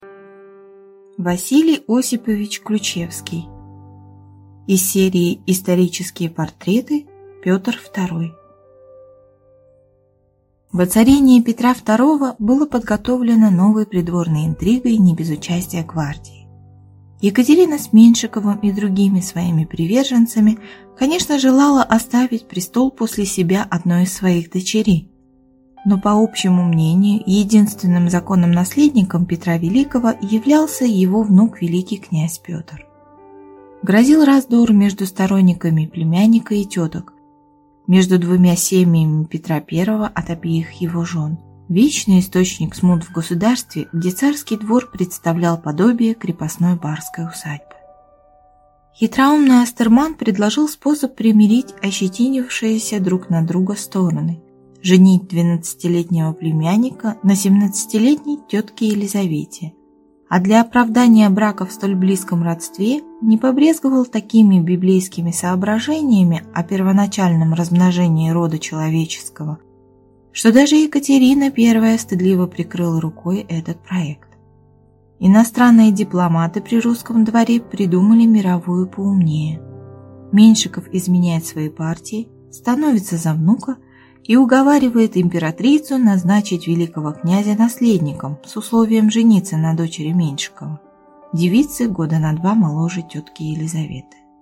Аудиокнига Петр II | Библиотека аудиокниг